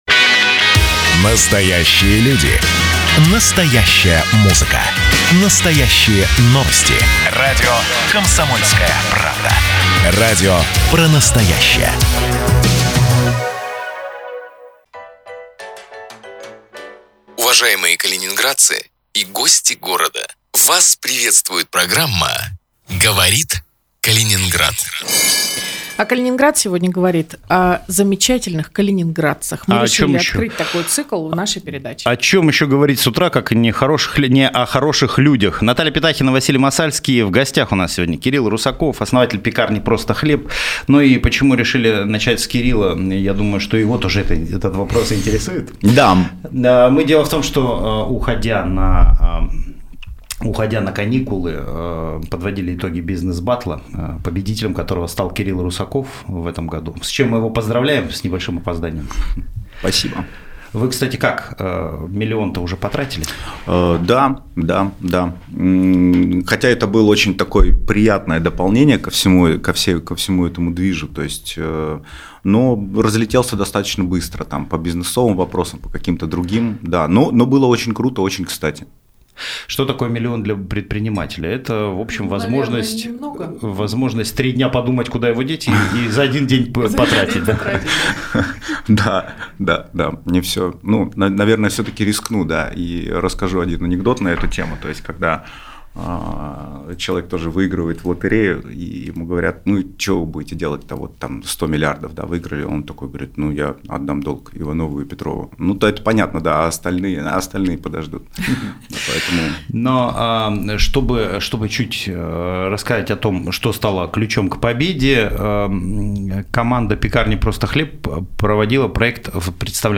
Каждое утро в прямом эфире обсуждаем городские новости.